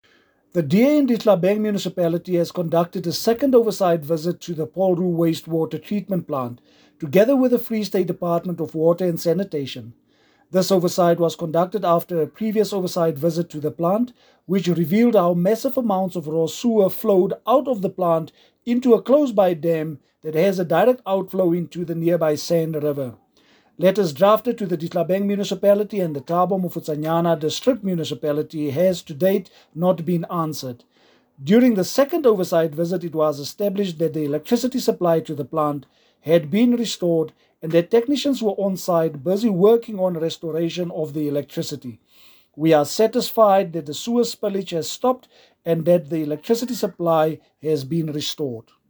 English soundbite by